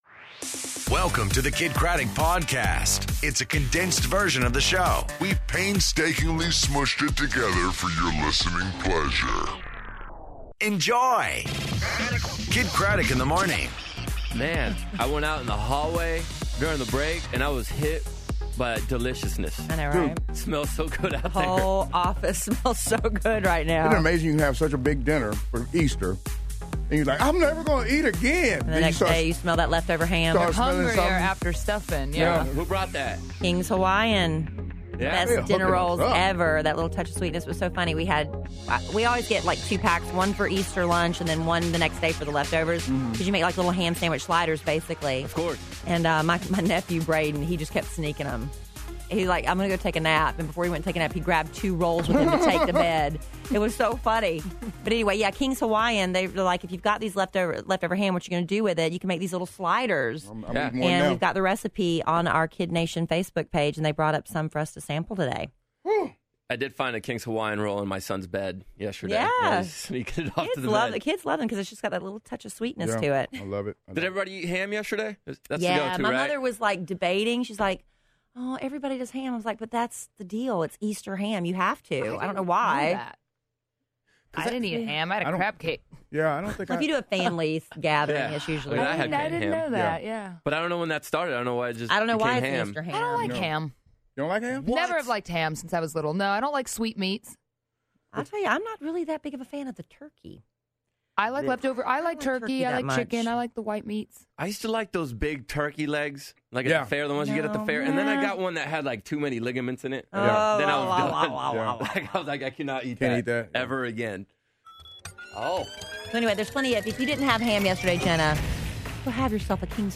Nick Jonas On The Phone